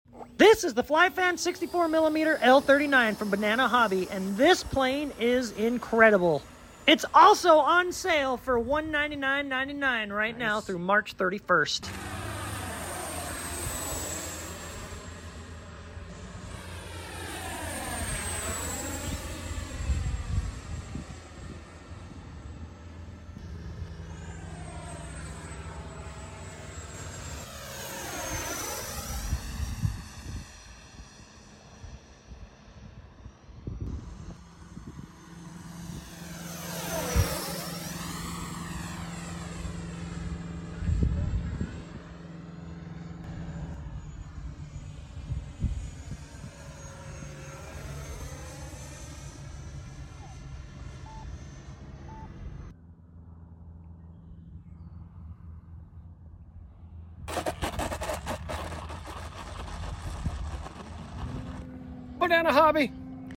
Best sounding 64mm Jet you’ve sound effects free download
Best sounding 64mm Jet you’ve ever heard!